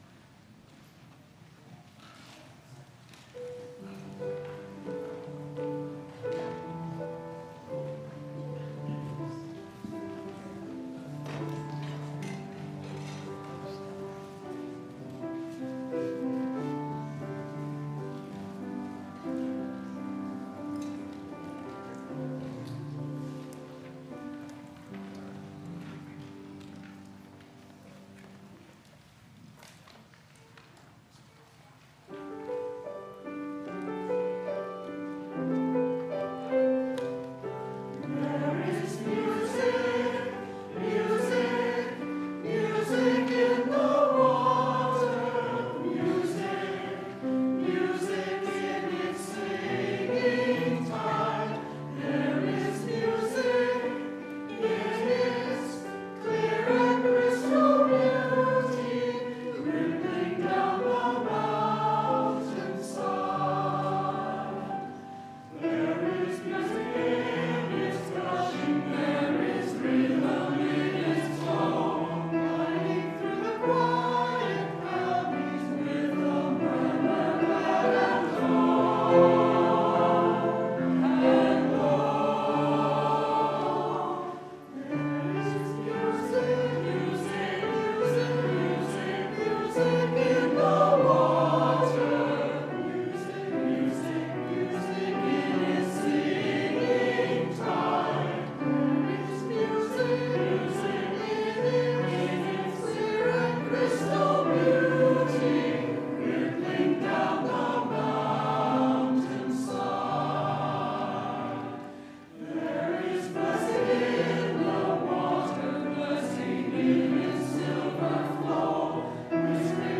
Choir and Instrumental Music
As performed by the UUSS Choir – September 8, 2019